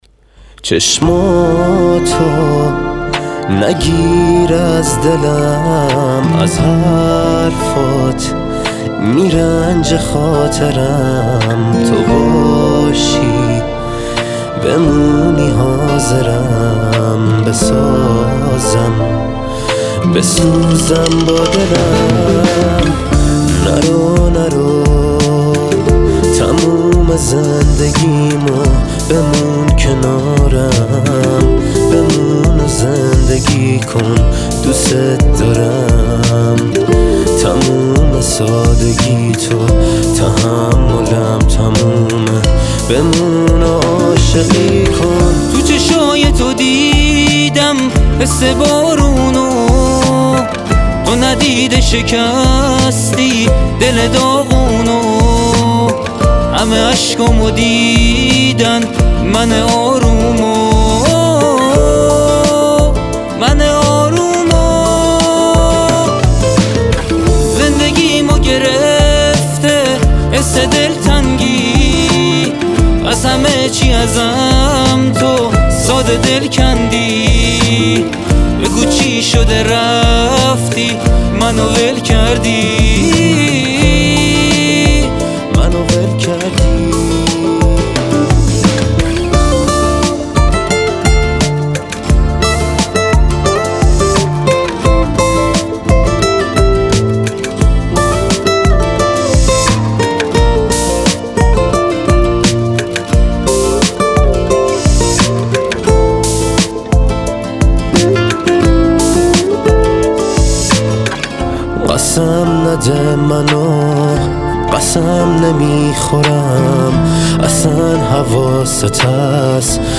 موضوعات: تک آهنگ, دانلود آهنگ پاپ